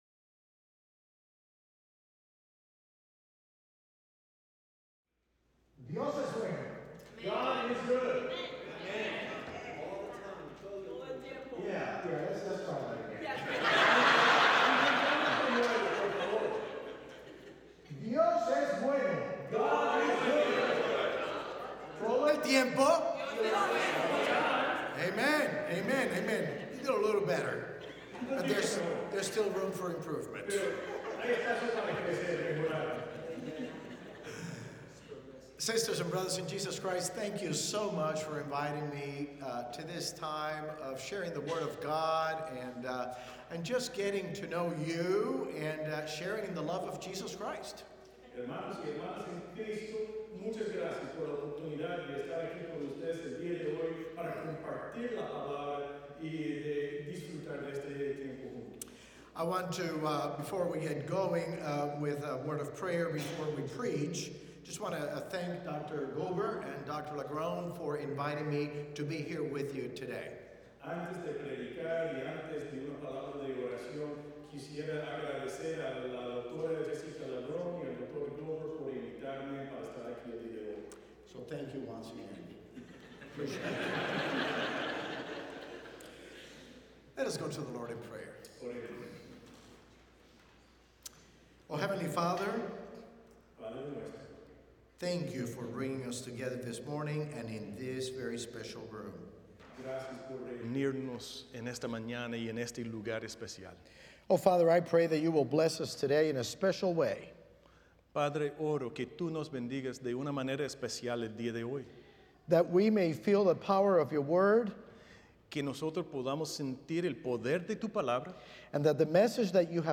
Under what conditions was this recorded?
The following service took place on Thursday, October 2, 2025.